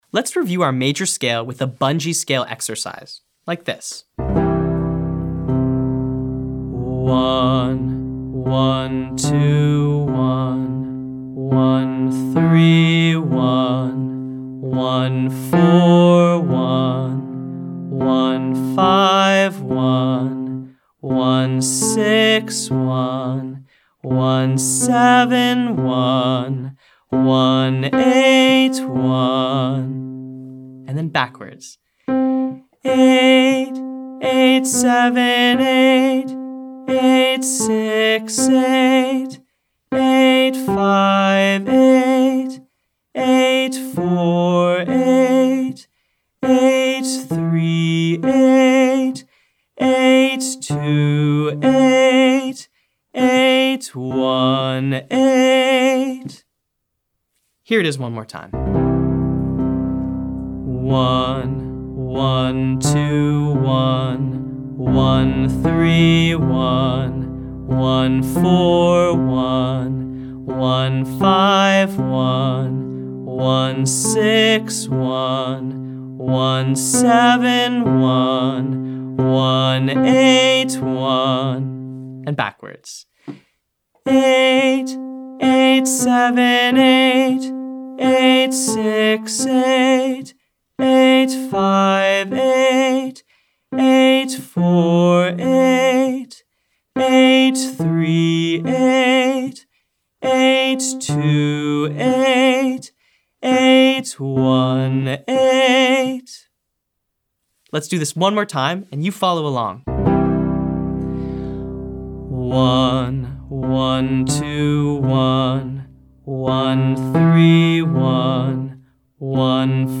Exercise 3: Scale and pitch work
Let's review our major scale with a bungee scale exercise